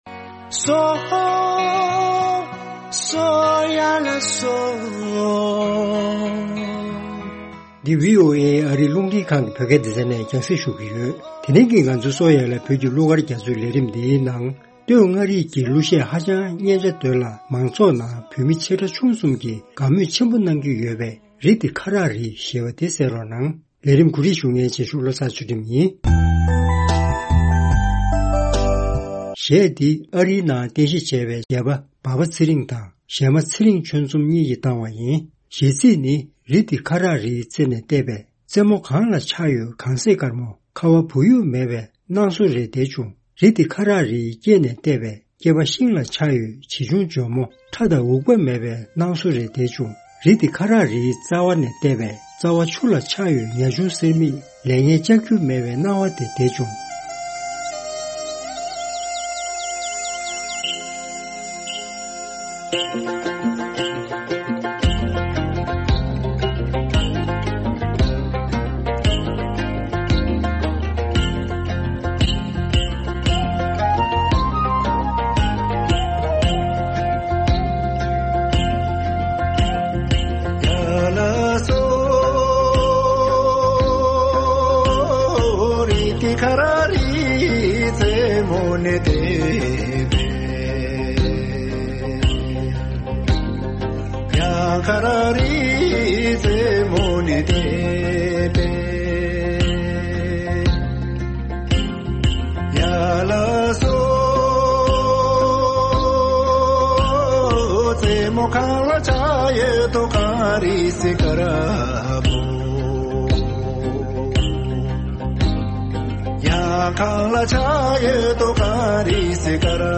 སྟོད་མངའ་རིའི་གླུ་གཞས་ ཁ་རག་རི་ཞེས་པའི་གླུ།
Popular Ngari Song Kharag Ri